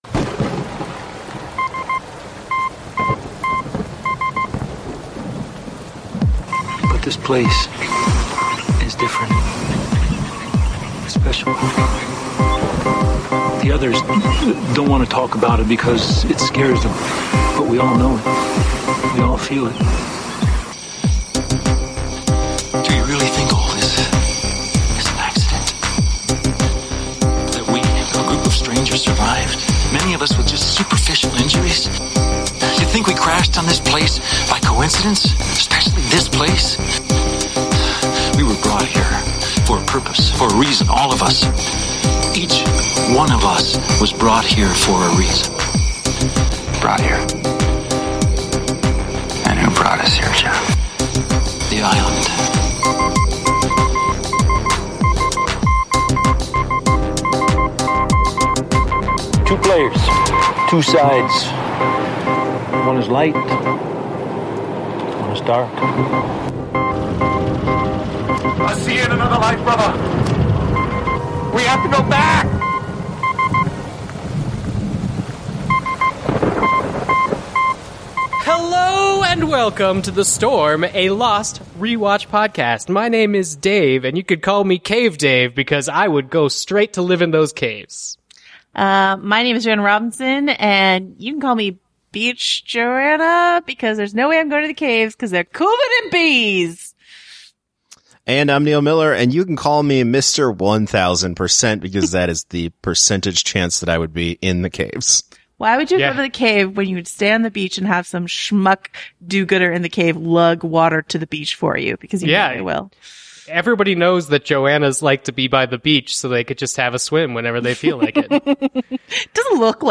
INTERVIEW (No Spoilers) - 45 minutes, 32 seconds THE STORM (SPOILERS!)